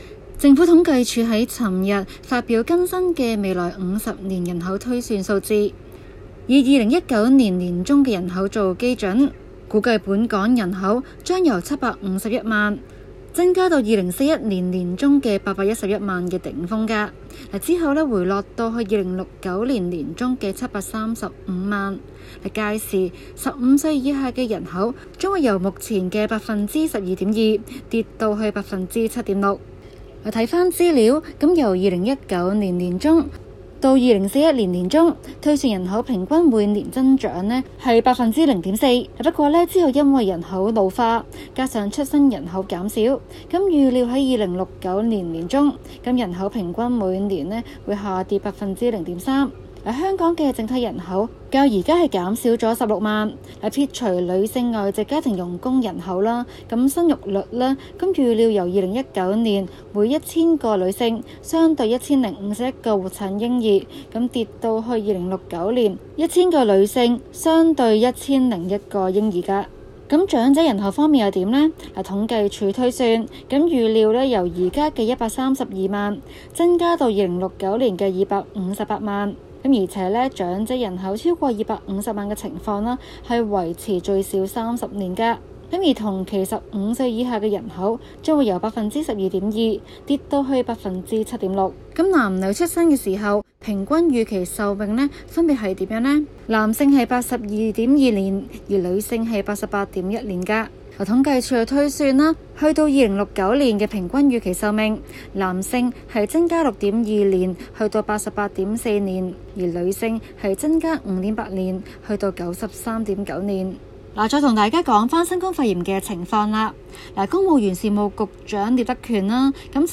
今期【中港快訊】報導港府伸延社區檢測計劃，希望更多市民參與。